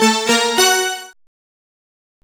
Synth Lick 49-10.wav